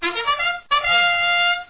start.wav